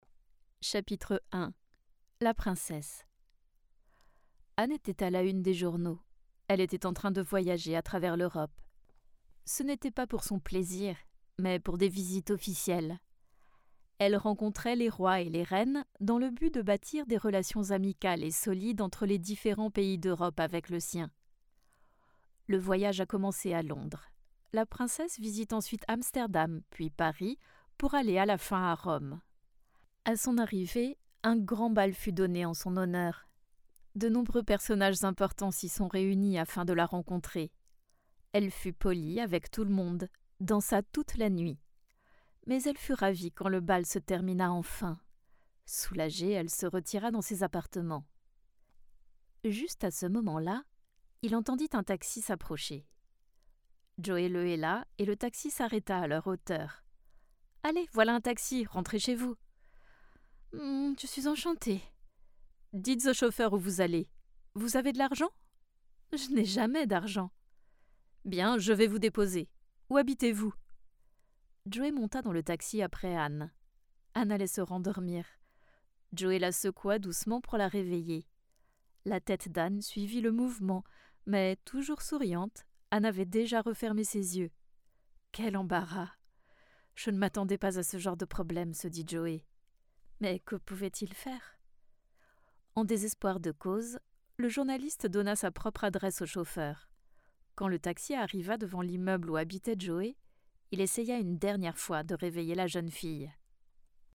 Female
Conversational, Cool, Energetic, Friendly, Funny, Natural, Reassuring, Smooth, Soft, Versatile, Warm, Young
Parisian (native)
I'm a French Parisian Voice talent and a trained actress.
SHOWREEL COMMERCIAL.mp3
Microphone: Audiotechnica 4040